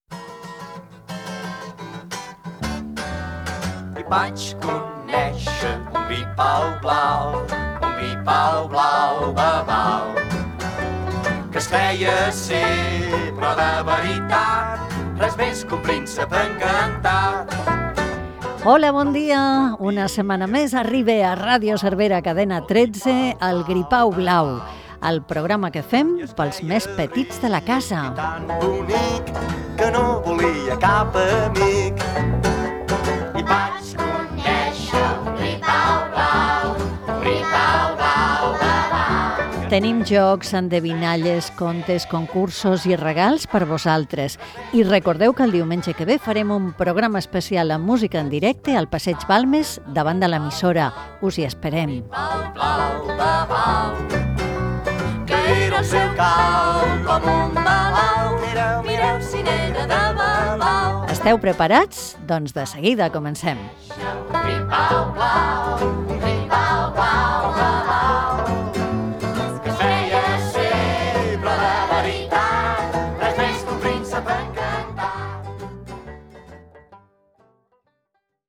Sintonia i presentació del programa amb l'anunci d'un programa que es farà davant de l'emissora
Infantil-juvenil
Recreació feta per Pepa Fernández el mes de maig de 2024, en no conservar-se cap enregistrament seu de Ràdio Cervera.